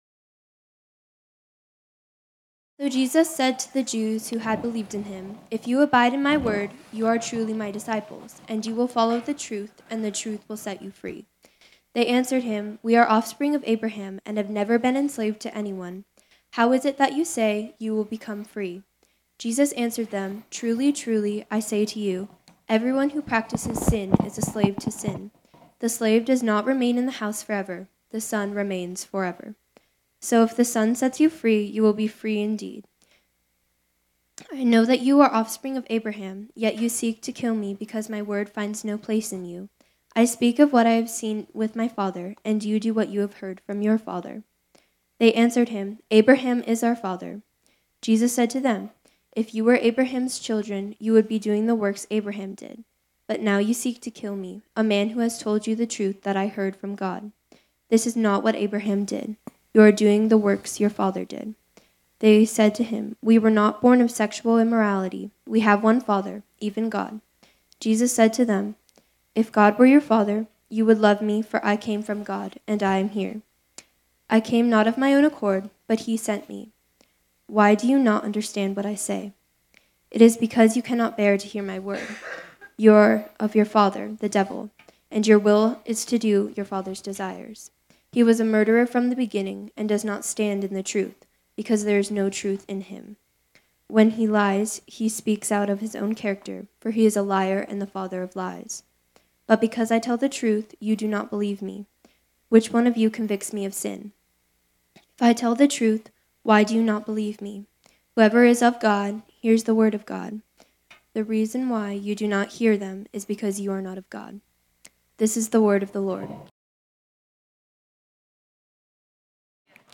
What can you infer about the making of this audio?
This sermon was originally preached on Sunday, January 12, 2020.